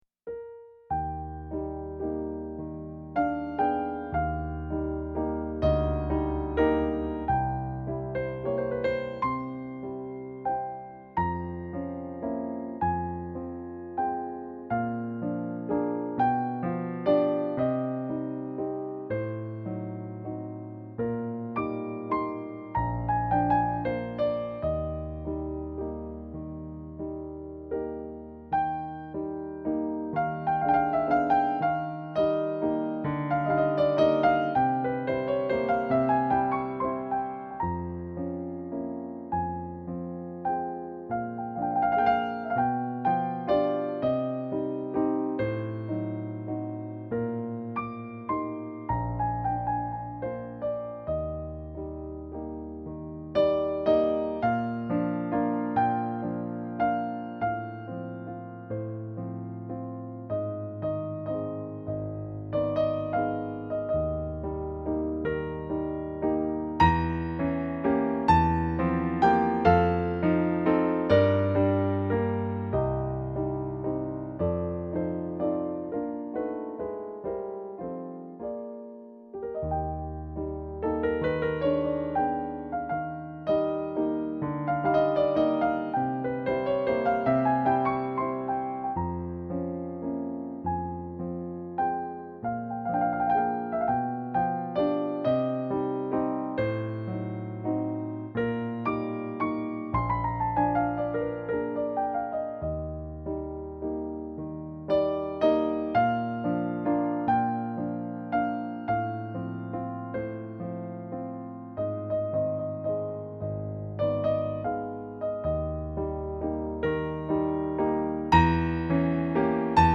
Mon piano : yamaha P140, C. Bechstein B210
oui, c'est saturé, mais très légèrement.
lien et même fausses notes), ceci dit je trouve le son moins brutal, peut-être juste une impression mais bon ...
1) A la 7ème mesure, les petites notes me semblent un peu "brusques".